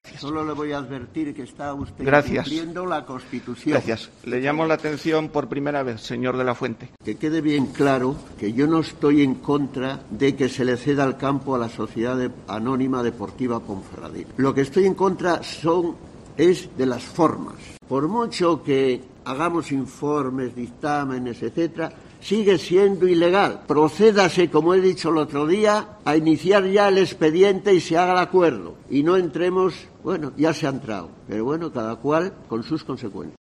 Escucha aquí al concejal ocialista díscolo Manuel de la Fuente